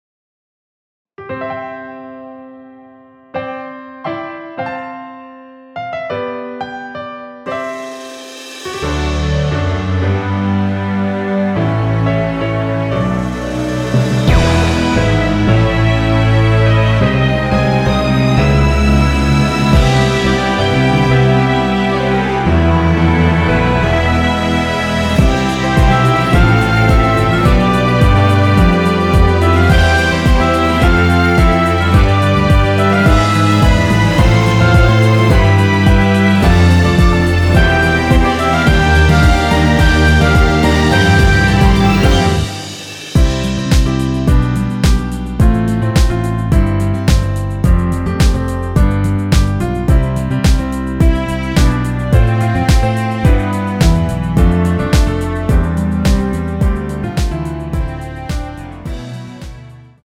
1절후 후렴구로 진행 되게 편곡 하였습니다.(가사및 미리듣기 참조)
◈ 곡명 옆 (-1)은 반음 내림, (+1)은 반음 올림 입니다.
앞부분30초, 뒷부분30초씩 편집해서 올려 드리고 있습니다.
중간에 음이 끈어지고 다시 나오는 이유는